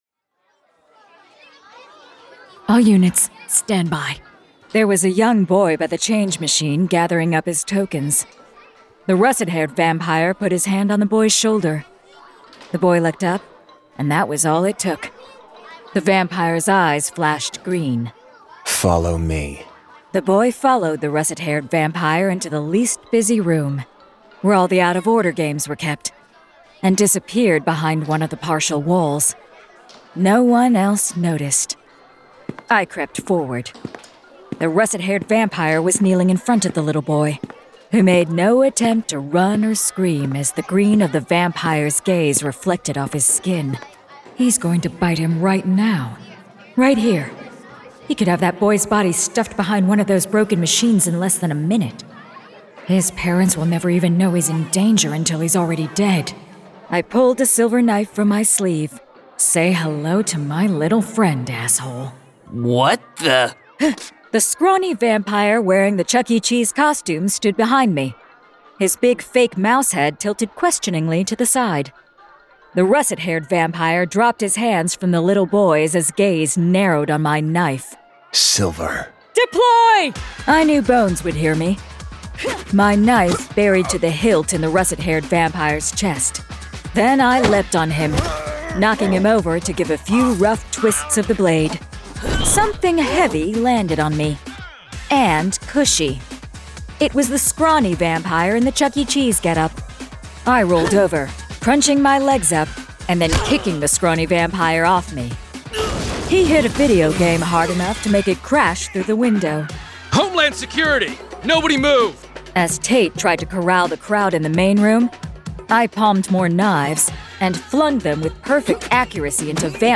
Full Cast. Cinematic Music. Sound Effects.
[Dramatized Adaptation]
Genre: Fantasy Romance